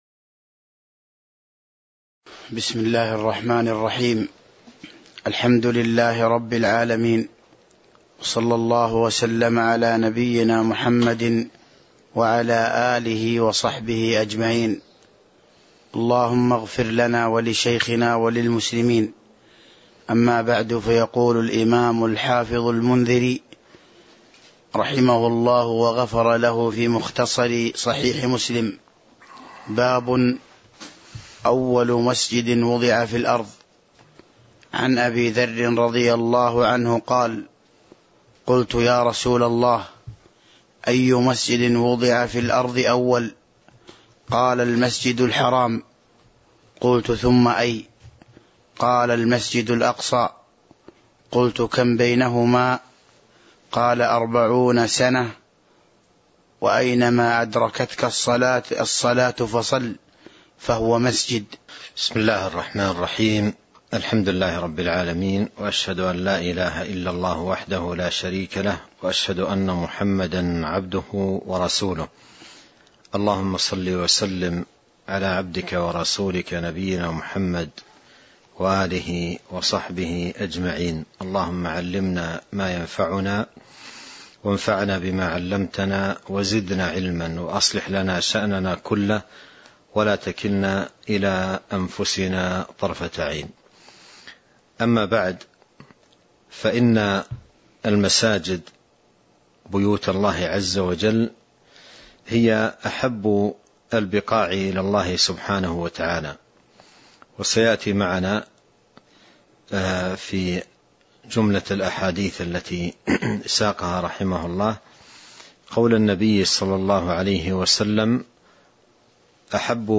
تاريخ النشر ١٩ ربيع الثاني ١٤٤٢ هـ المكان: المسجد النبوي الشيخ